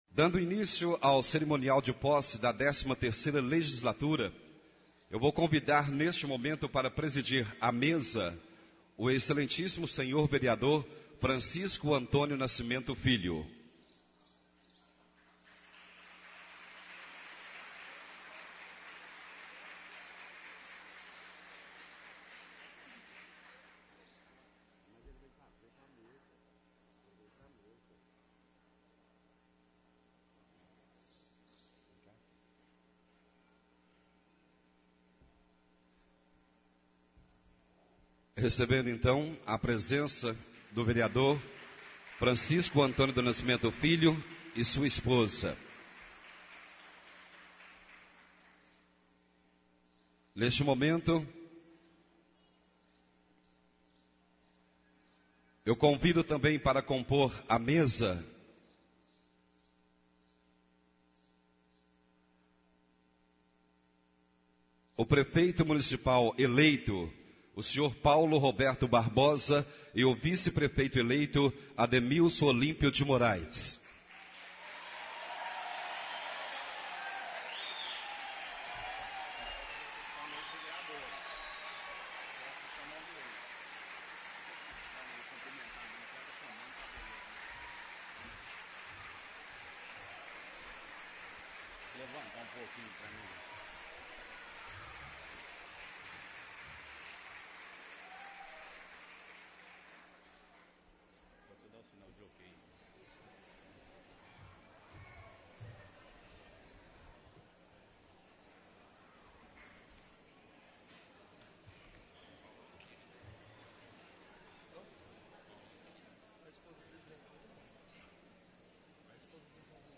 Sessão Solene (Posse) - 01/01/13 — CÂMARA MUNICIPAL DE PLANURA